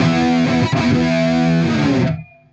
Index of /musicradar/80s-heat-samples/95bpm
AM_HeroGuitar_95-E02.wav